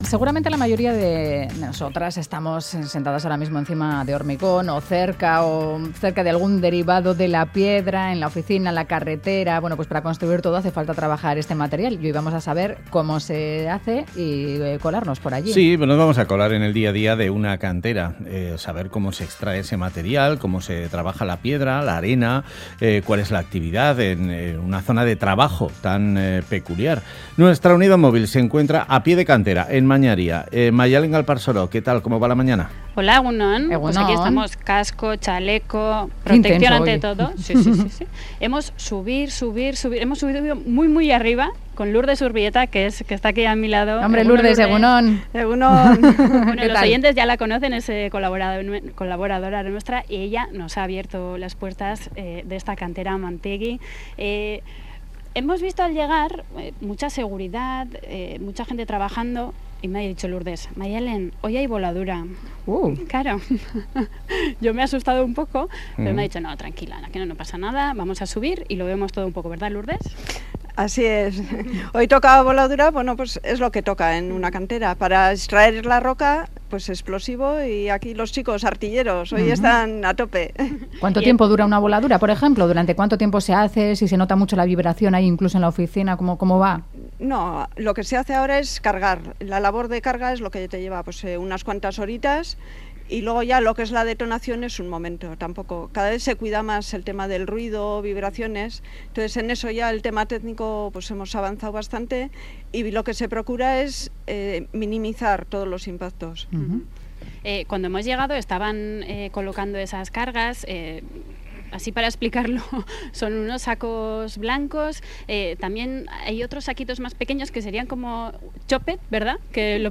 Audio: Visitamos la cantera del Grupo Amantegi en Mañaria.